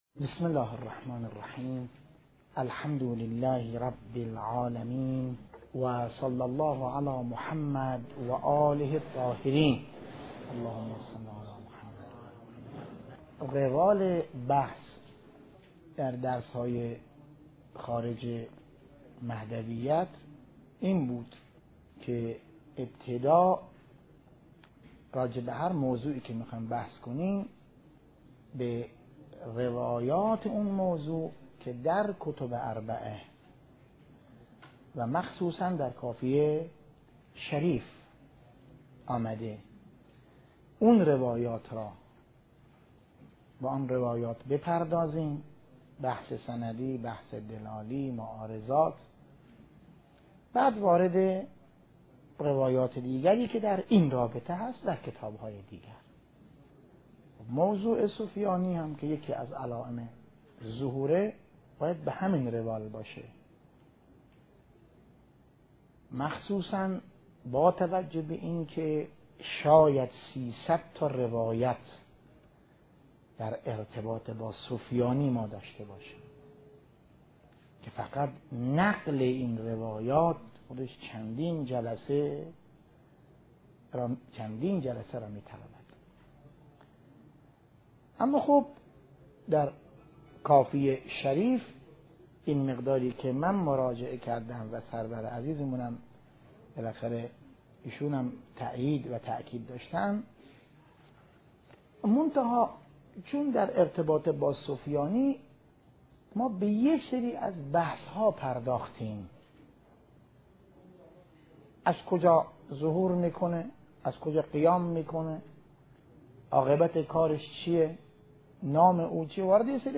بحث خارج مهدویت - بحث روایات سفیانی ج 4